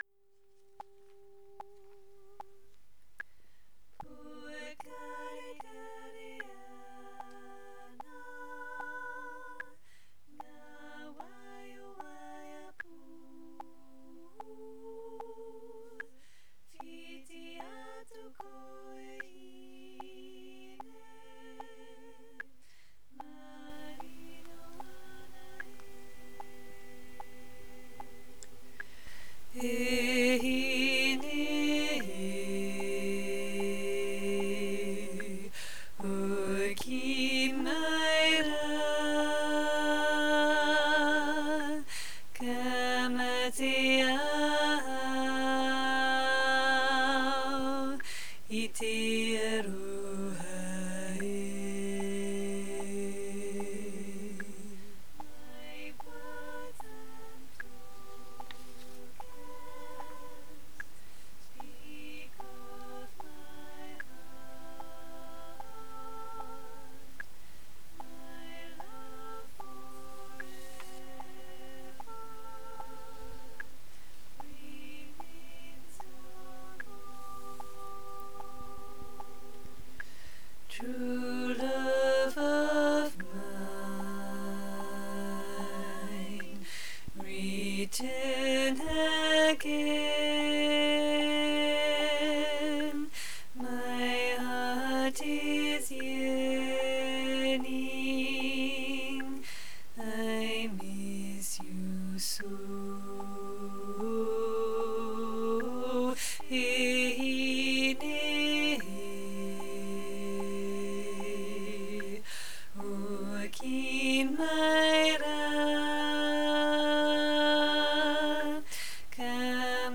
Pokarekare-Ana-TENOR.mp3